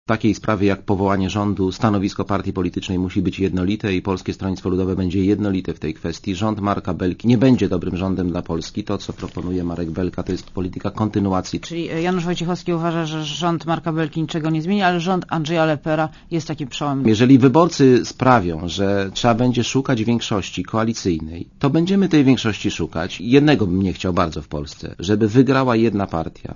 Prezes PSL Janusz Wojciechowski powiedział podczas rozmowy z Moniką Olejnik na antenie Radia ZET, że nie wyklucza budowania w przyszłości koalicji PSL z Ligą Polskich Rodzin i Samoobroną.